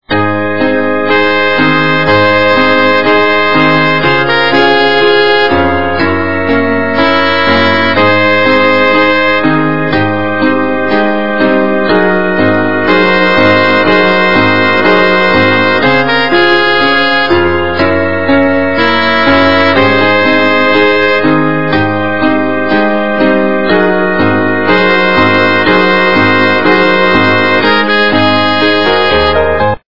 качество понижено и присутствуют гудки
полифоническую мелодию